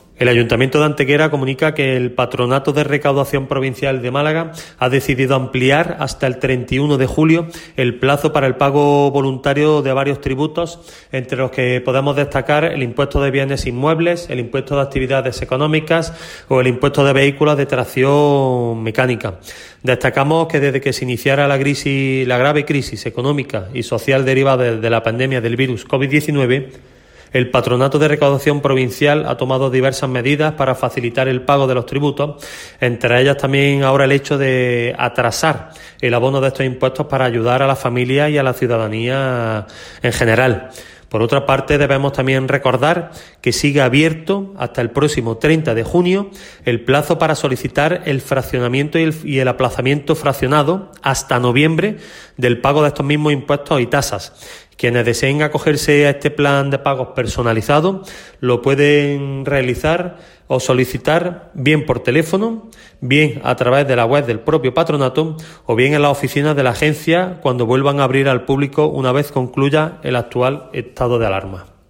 El teniente de alcalde delegado de Hacienda del Ayuntamiento de Antequera, Antonio García, destaca el hecho de que se decida realizar esta ampliación de plazo, pudiendo beneficiar así aliviar las consecuencias de la pandemia.
Cortes de voz